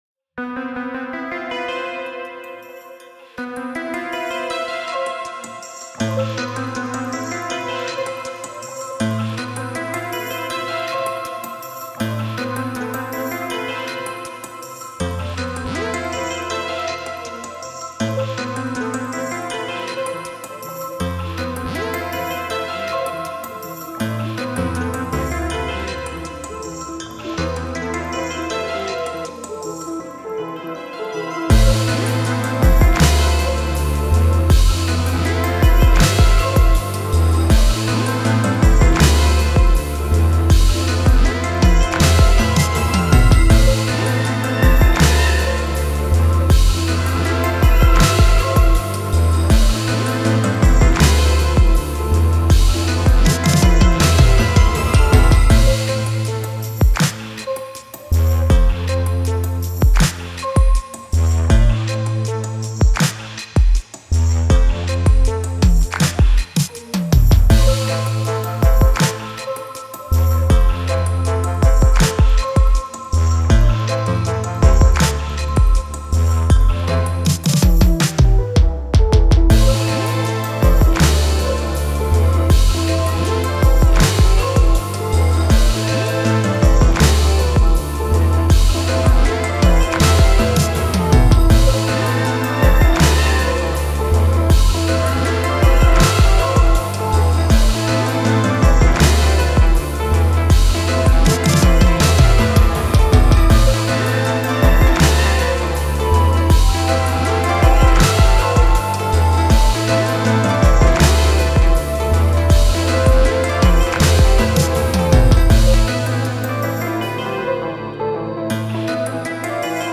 Mysterious 80s synths with a slow beat, urban drama ensues.